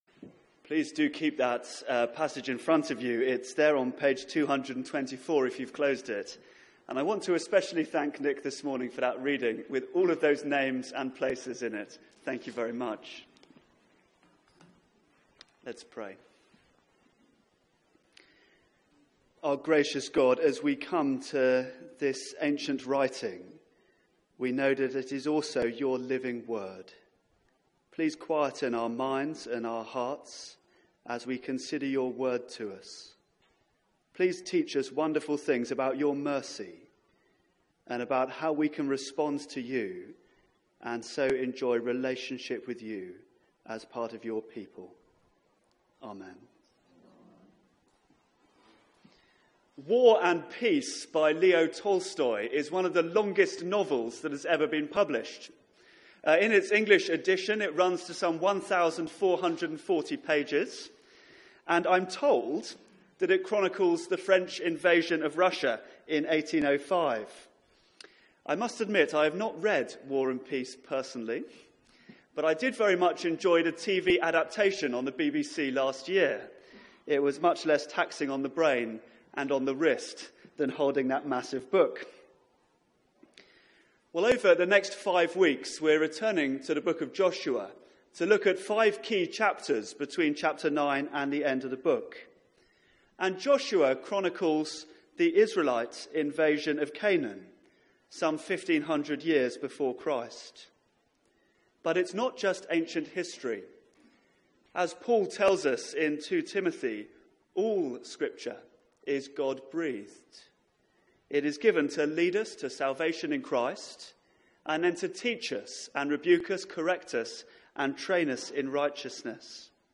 Media for 9:15am Service on Sun 23rd Jul 2017
Series: Great is thy faithfulness Theme: Responding to God Sermon (11:00 Service)